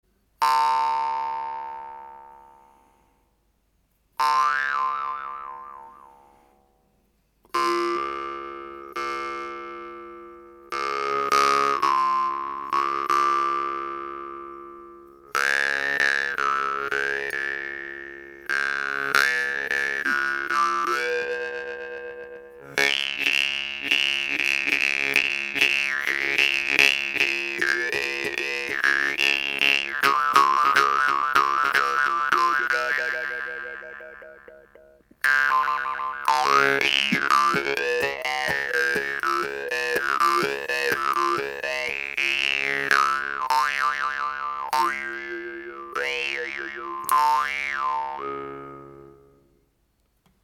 Douce, fine et assez souple, la Khomus TARTAKOV est un excellent instrument facile à faire sonner, sans avoir à se battre! Cette guimbarde est très sensible au souffle et permet de jouer mélodiquement, elle est néanmoins puissante.